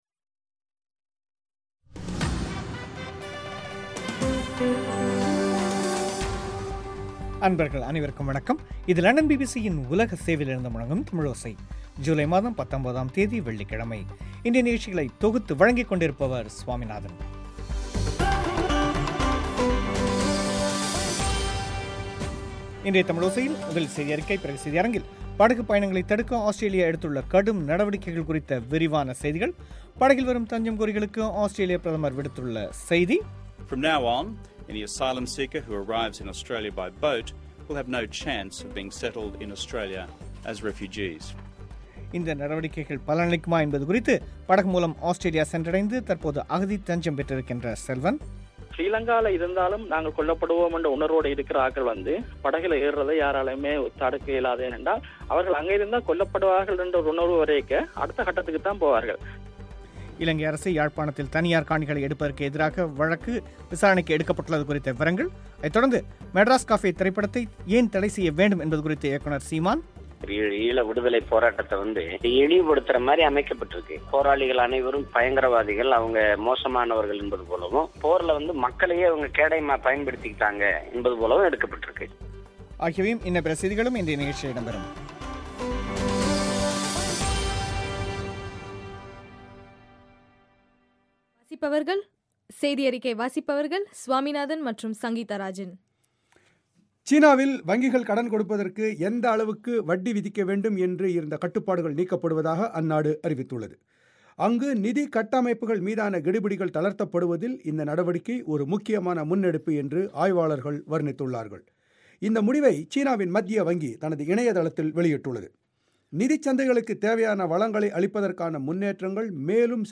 இன்றைய தமிழோசையில் முதலில் செய்தியறிக்கை பிறகு செய்தியரங்கில்